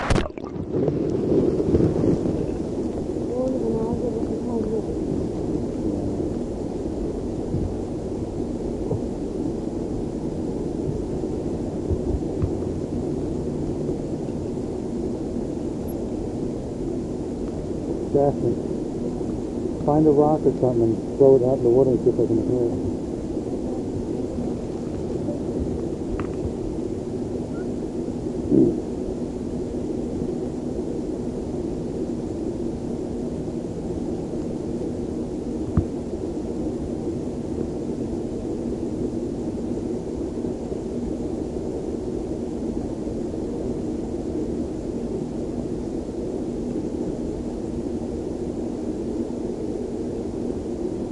自来水
描述：在池中落水..
标签： 水花四溅 运行